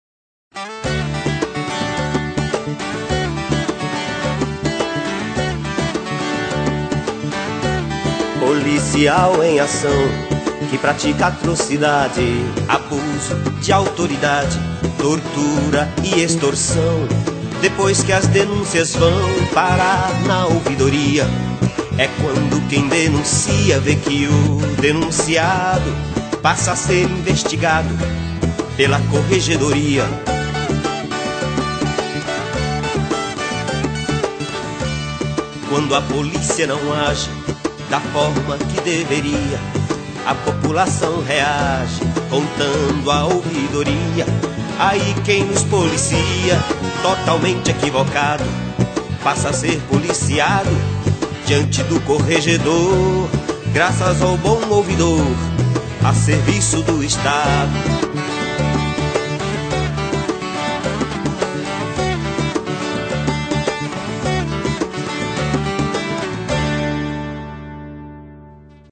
Faixa 6 - MPB 1 Faixa 13 - Spot Rap